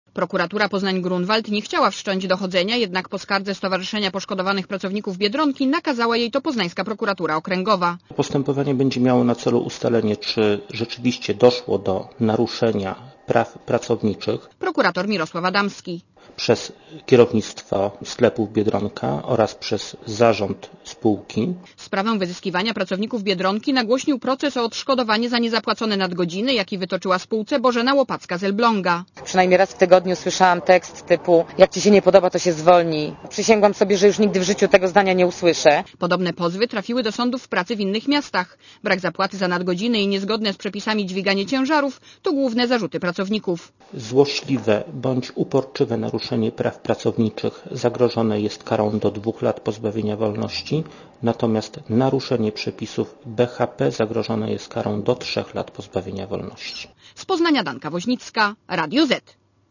Relacja reportera Radia ZET Prokuratura Okręgowa w Poznaniu poleciła wszcząć śledztwo w tej sprawie Prokuraturze Rejonowej Poznań-Grunwald.